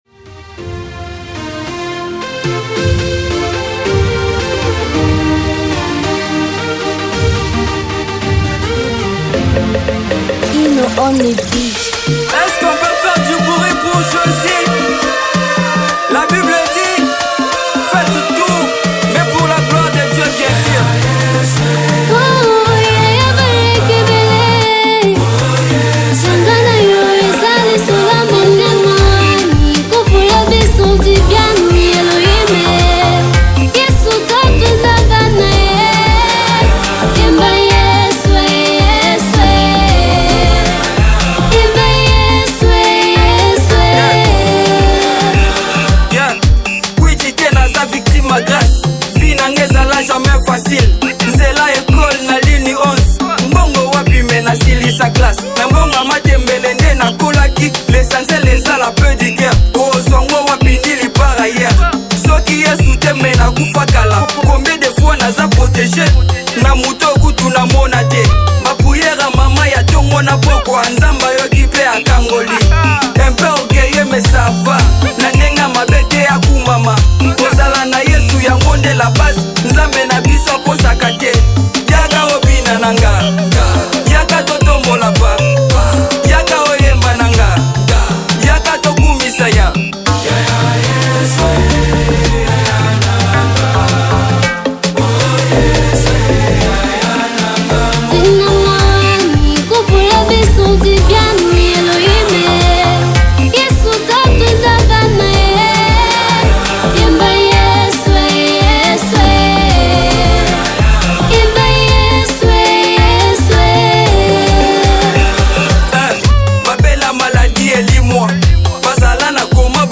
International Gospel Songs
is a heartfelt praise unto God for His supremacy.